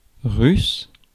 Ääntäminen
France: IPA: [ʁys]